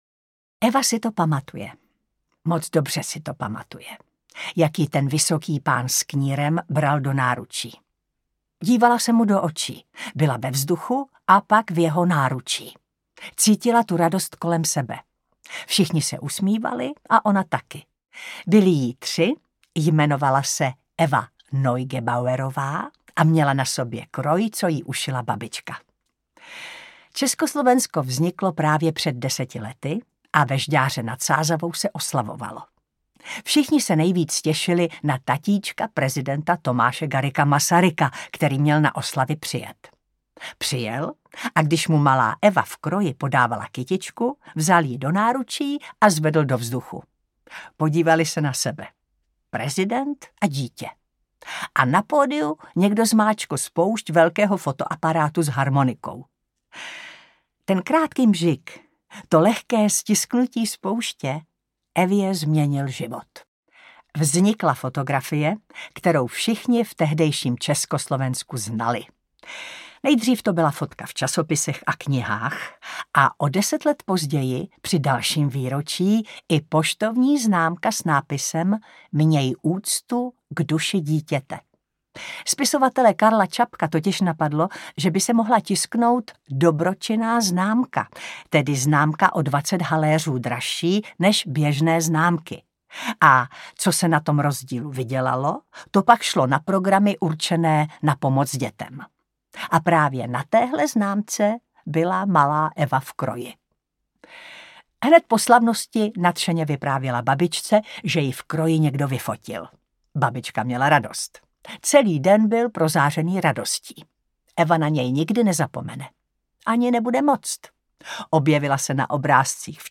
Holčička ze známky audiokniha
Ukázka z knihy